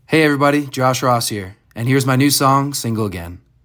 LINER Josh Ross (Single Again) 1